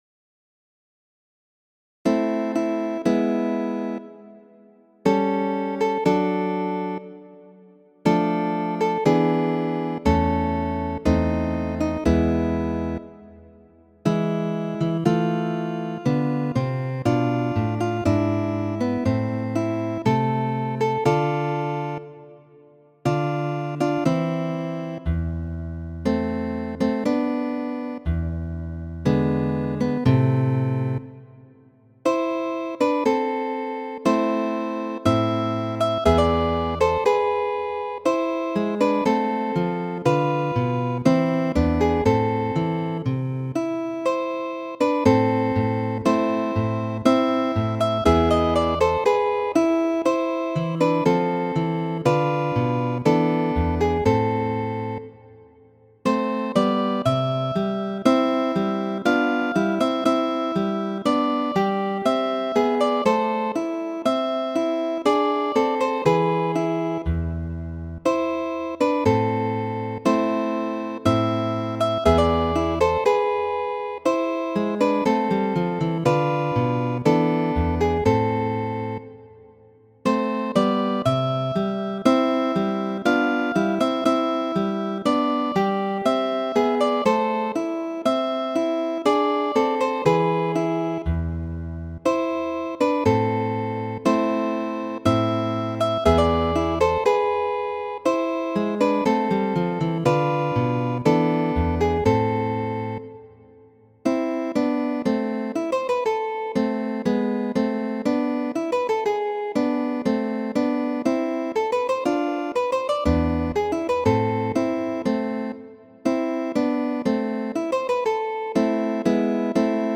Variaĵoj pri franca temo Que ne suis-je de la fougère, komponita de Fernando Sor en 1827.